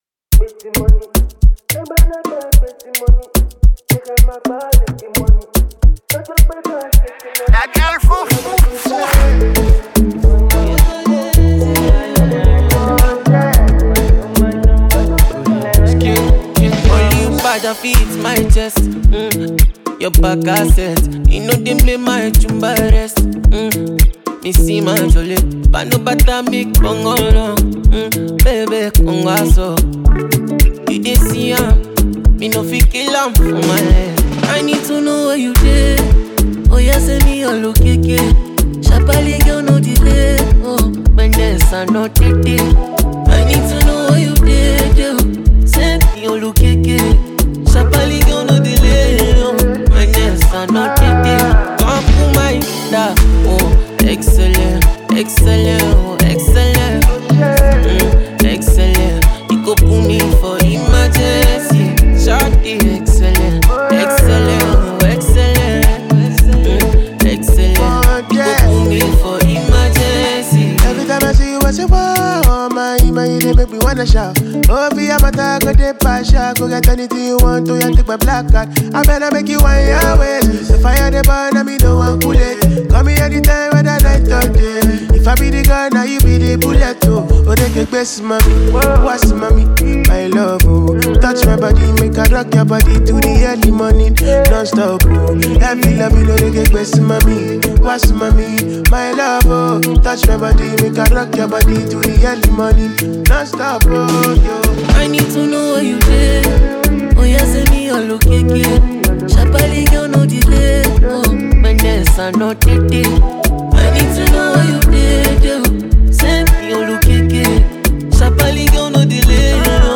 a Ghanaian Afro artist